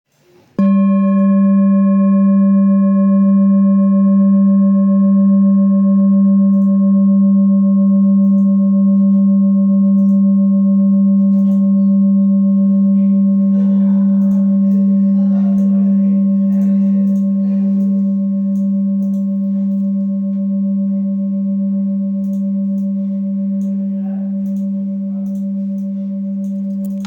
Full Moon Bowl, Buddhist Hand Beaten, Moon Carved, Antique Finishing, Select Accessories
Material Seven Bronze Metal
This is a Himalayas handmade full moon singing bowl. The full moon bowl is used in meditation for healing and relaxation sound therapy.